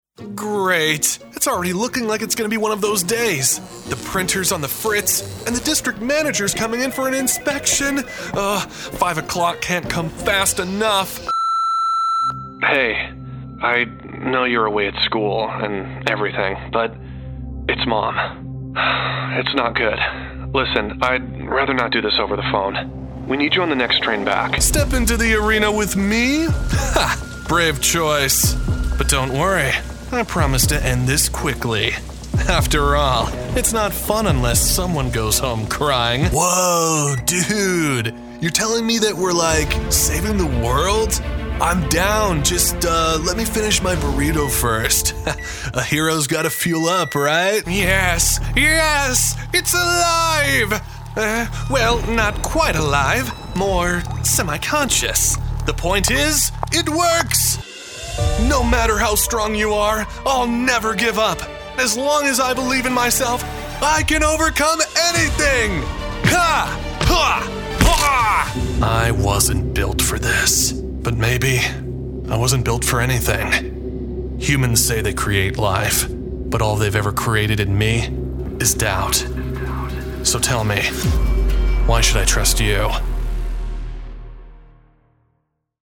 Voz seria y elegante, versátil con amplia experiencia en Documentales, comerciales, audiolibros, narrativa, publicidad, Corporativo, Doblaje, TVE, IVR, elearning, audioguías etc....
Spanish - Spain (Castilian) Adult (30-50) | Older Sound (50+)